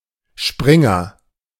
دار نشر شبرينغر (بالألمانية: Springer) (IPA:ˈʃpʁɪŋɐ)